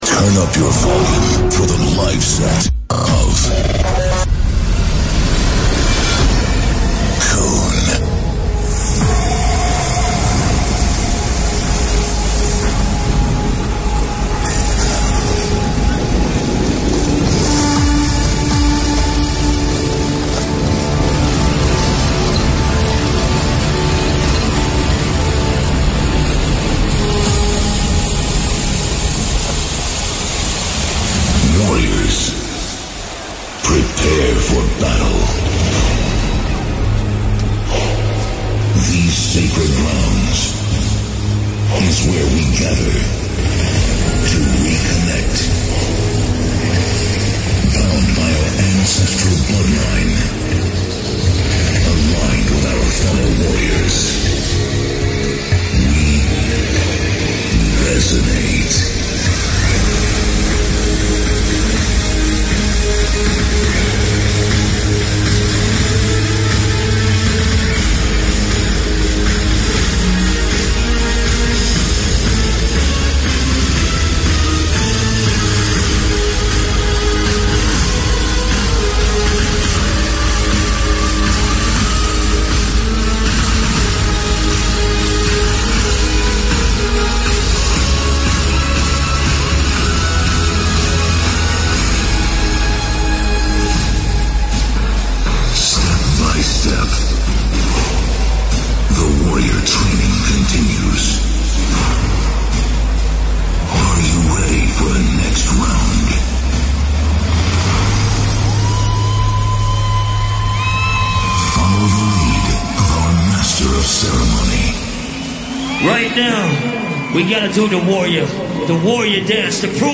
Also find other EDM
Liveset/DJ mix